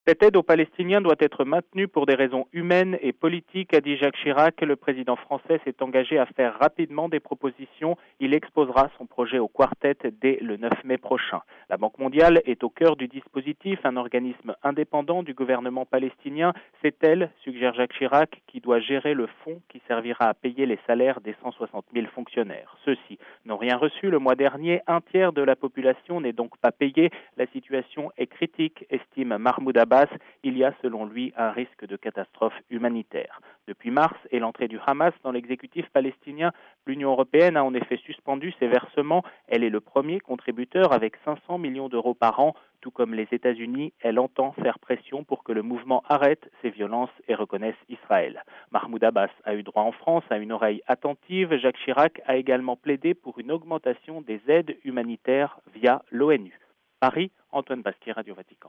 A Paris